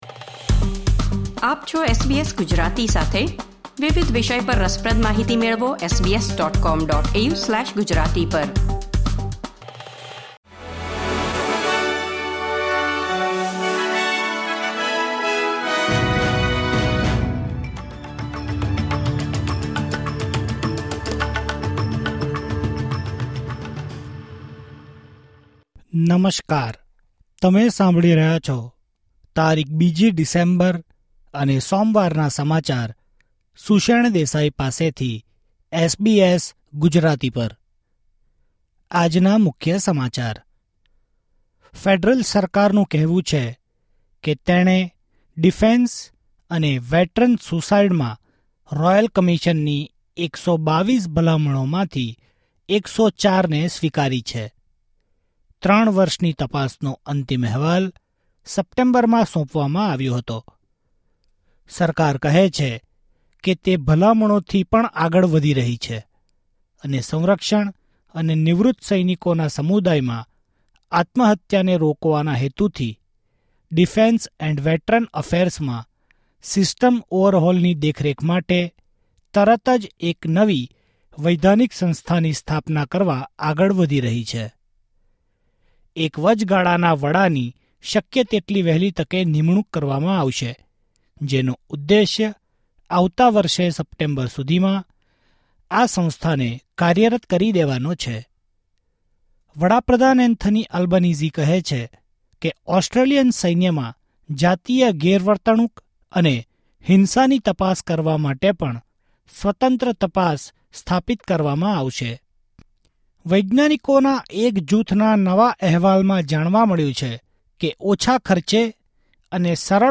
SBS Gujarati News Bulletin 2 December 2024